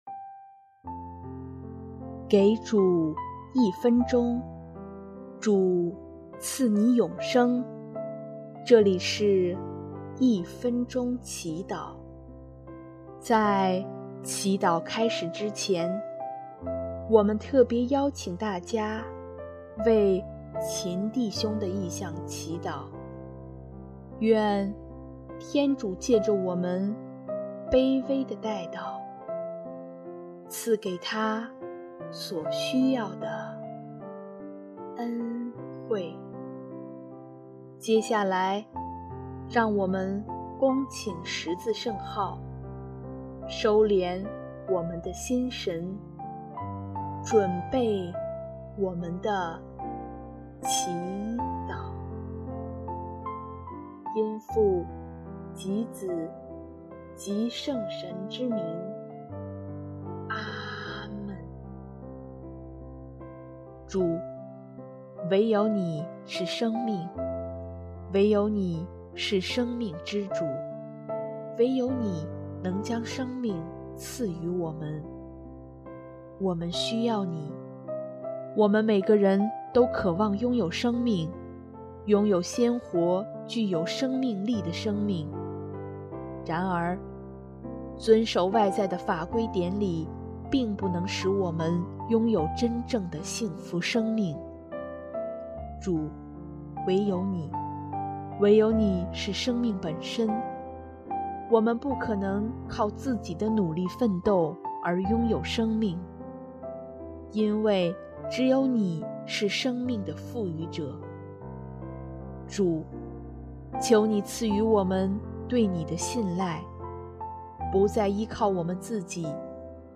【一分钟祈祷】|12月19日 在耶稣内得享生命